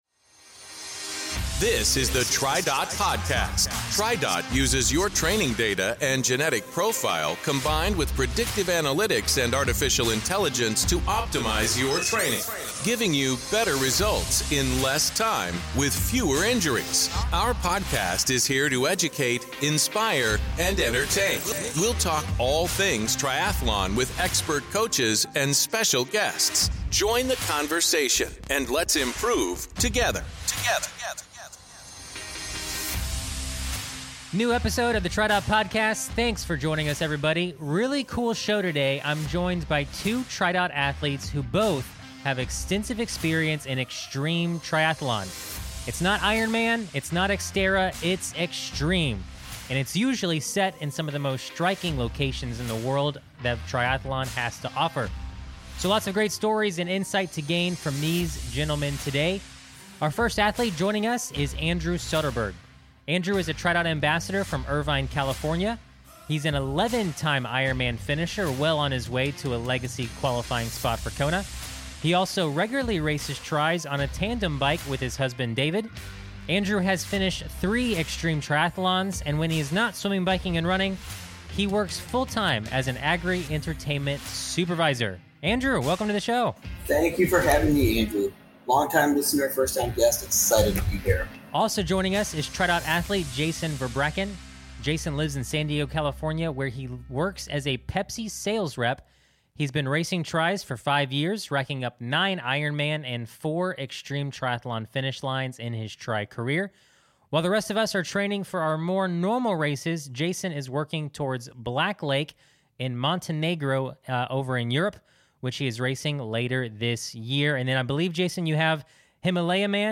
On today's episode, join two TriDot athletes who have embraced, and conquered, numerous Xtreme Triathlons.